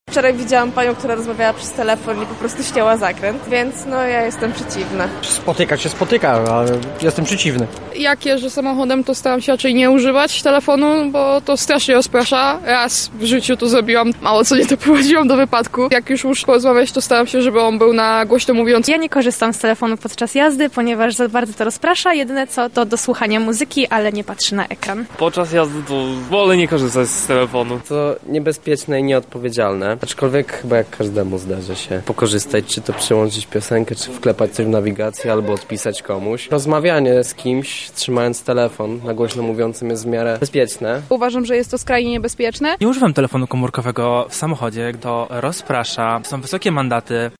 Zapytaliśmy mieszkańców Lublina, co sądzą na temat używania smartphone’a w czasie kierowania pojazdem:
sonda